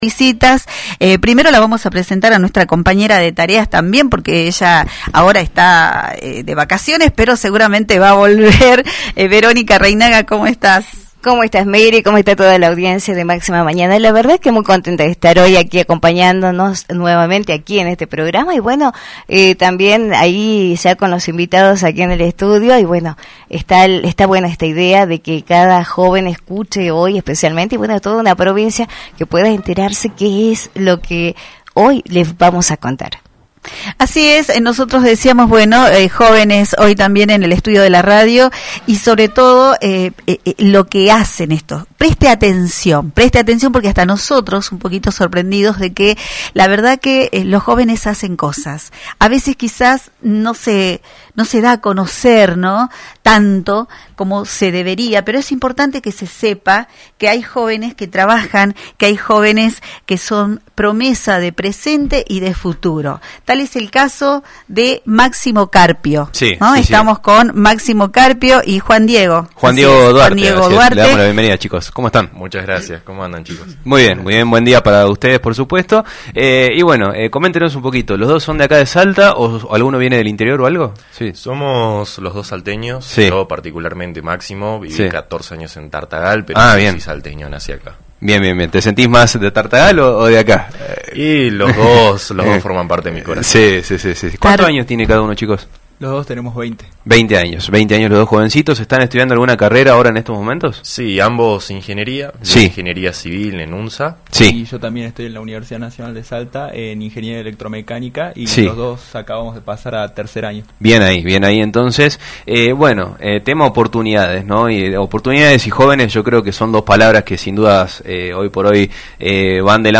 En una entrevista realizada en el programa Máxima Mañana